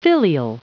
Prononciation du mot filial en anglais (fichier audio)
Prononciation du mot : filial